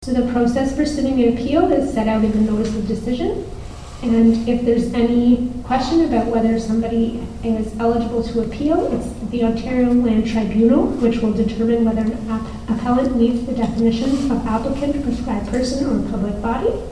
The matter was addressed at a Public Meeting of the Committee at the Nick Smith Centre in Arnprior February 5th.